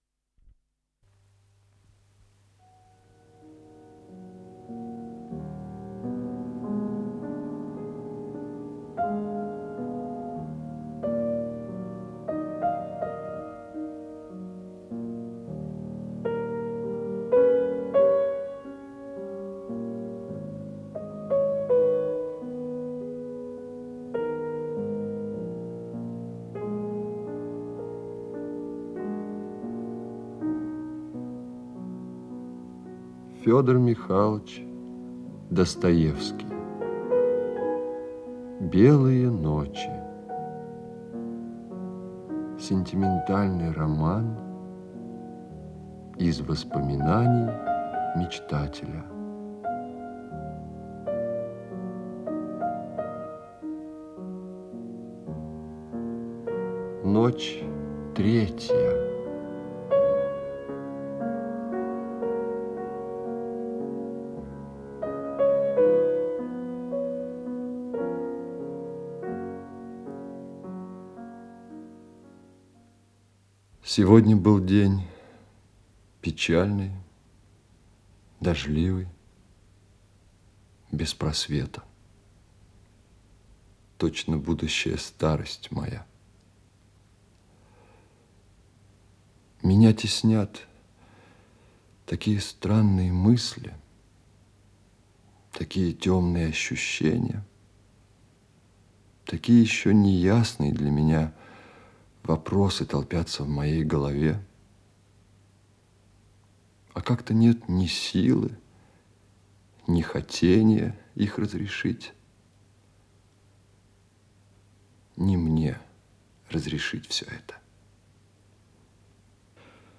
Исполнитель: Георгий Тараторкин - чтение
Ночь третья, повесть, передача 4-я